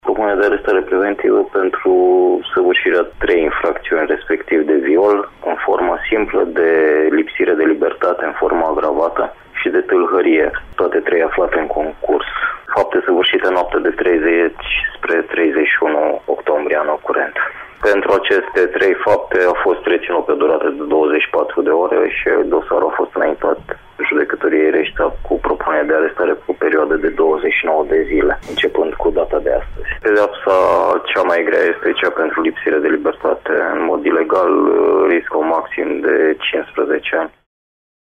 Judecător Rustin Ceasc, preşedintele Tribunalului Caraş-Severin: